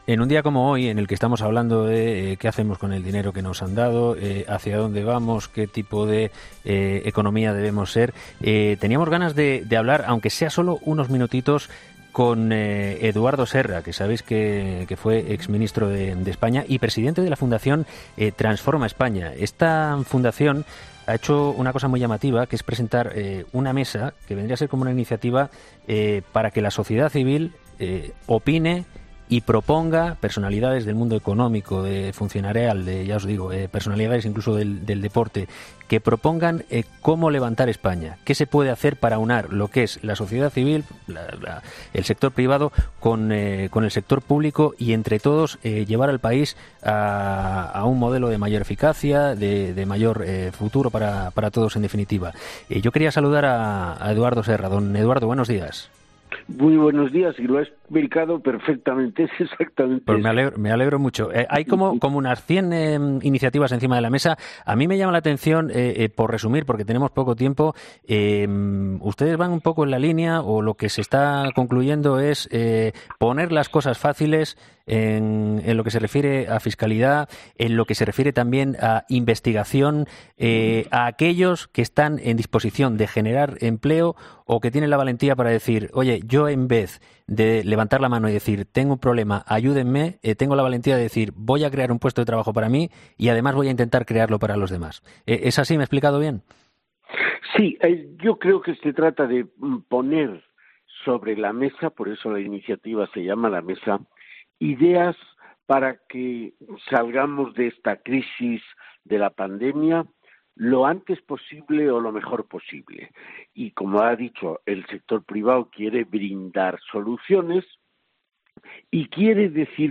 Esta mañana, en 'Herrera en COPE' hemos hablado con Eduardo Serra, exministro y presidente de la Fundación Transforma España.